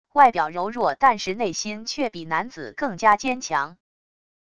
外表柔弱但是内心却比男子更加坚强wav音频生成系统WAV Audio Player